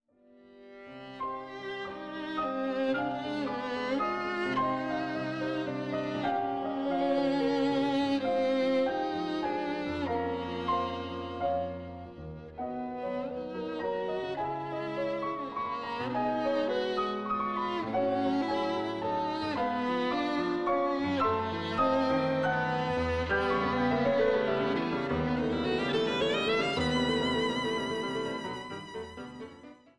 Violine
Gitarre
Klavier